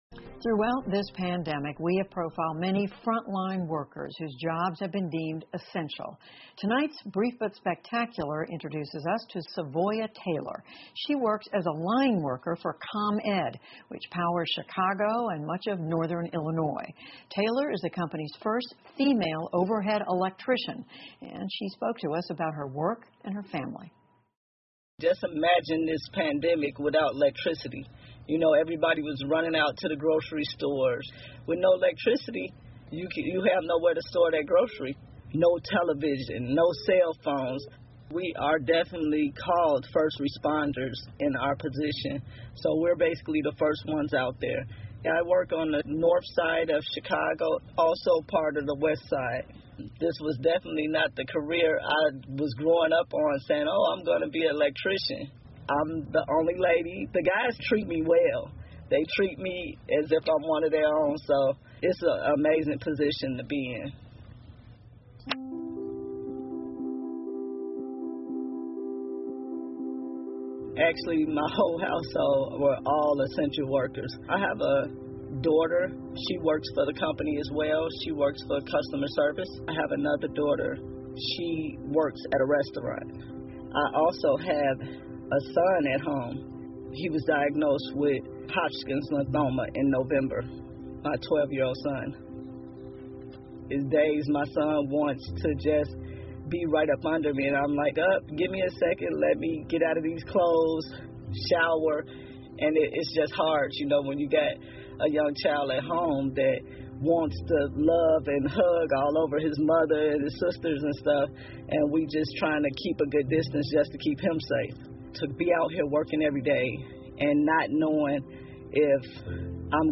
PBS高端访谈:女电线工人的人生 听力文件下载—在线英语听力室